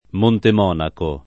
Montemonaco [ montem 0 nako ] top. (Marche)